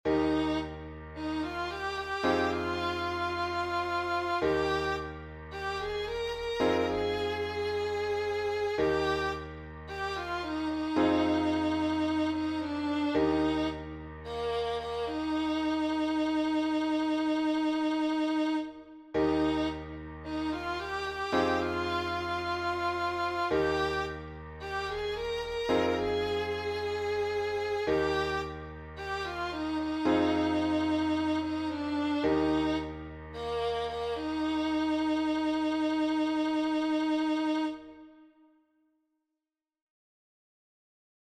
A cappella